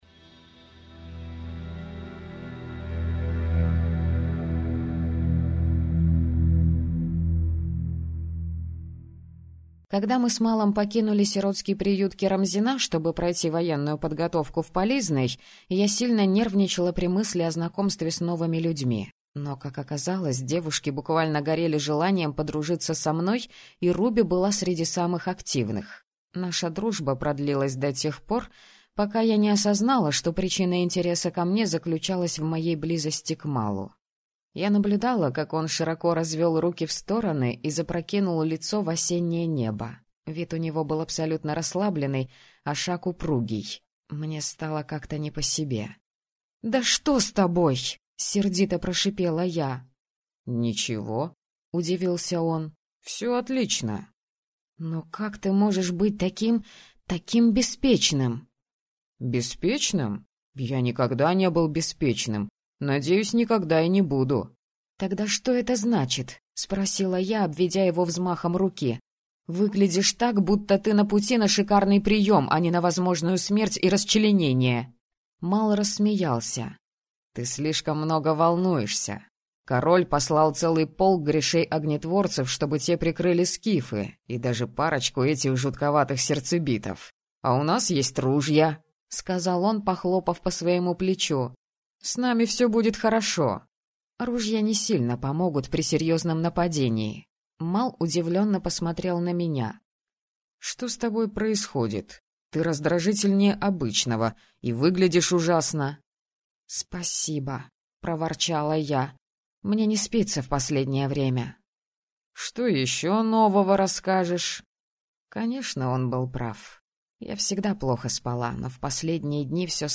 Аудиокнига Тень и кость | Библиотека аудиокниг